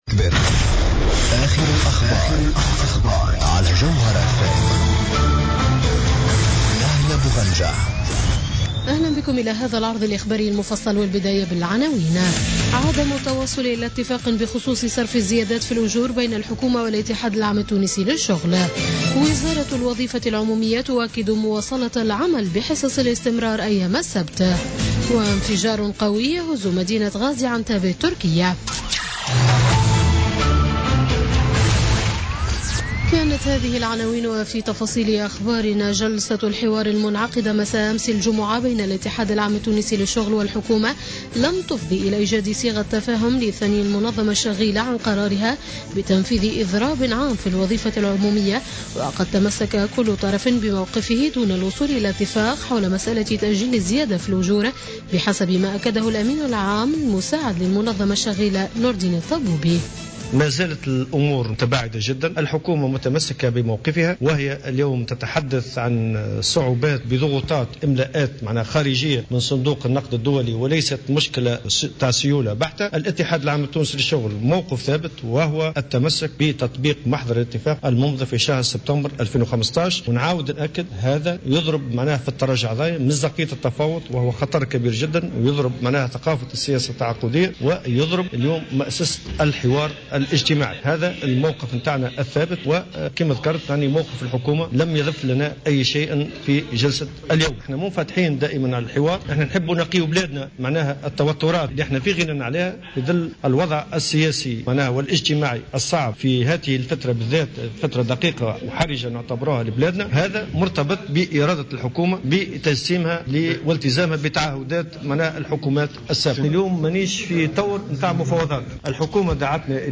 نشرة أخبارمنتصف الليل ليوم السبت 26 نوفمبر 2016